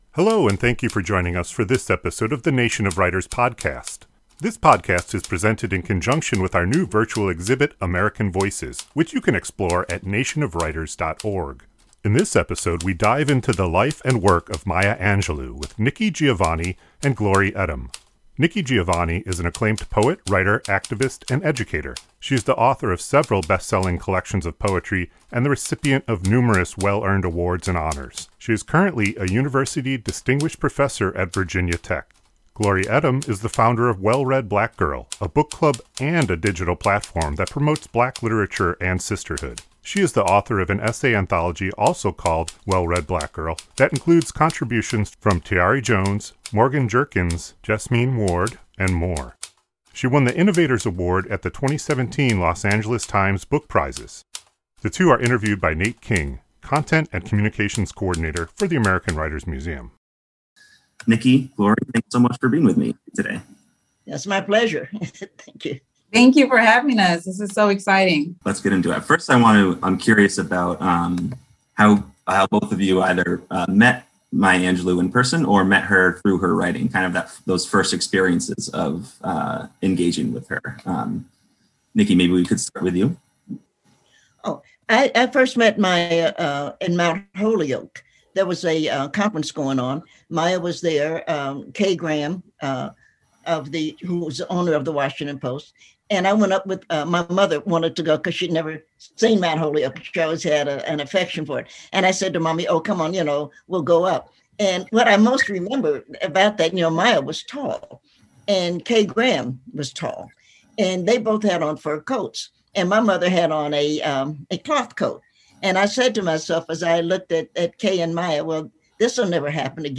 We chat with writers Nikki Giovanni